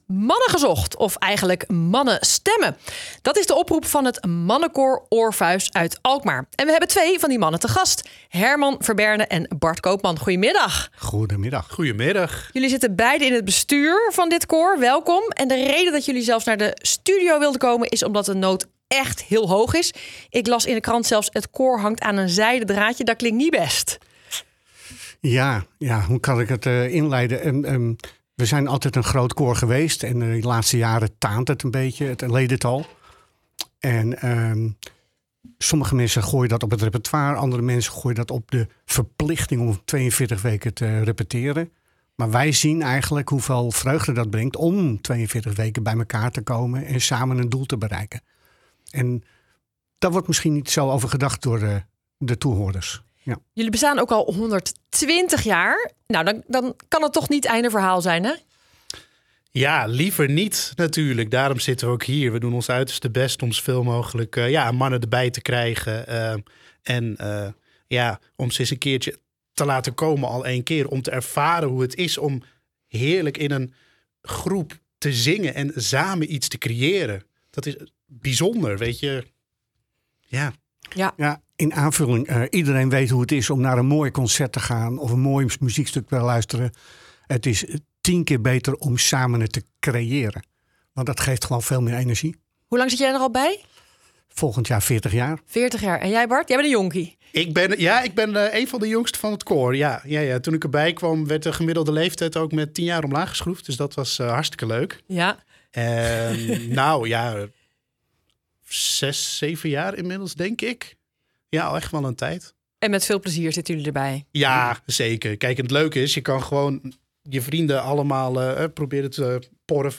OpenInterview
InterviewRadioNHhelpt-hetInterview.mp3